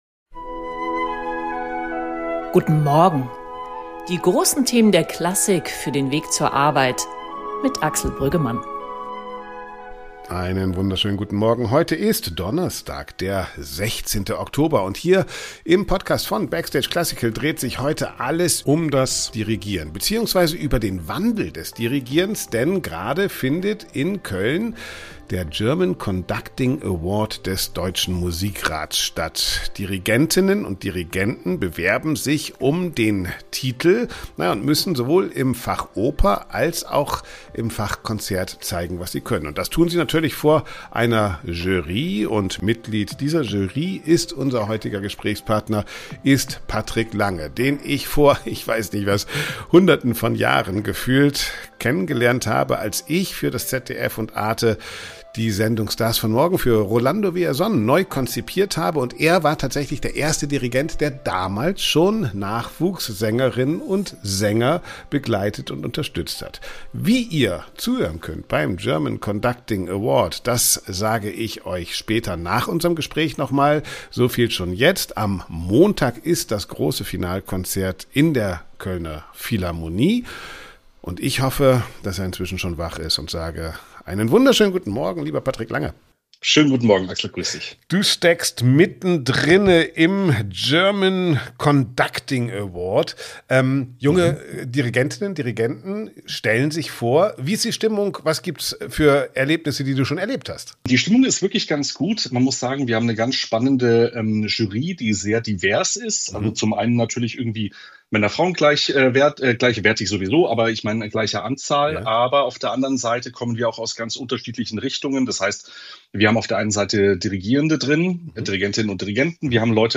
In Köln läuft der German Conducting Award.